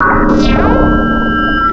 Cri de Spiritomb dans Pokémon Diamant et Perle.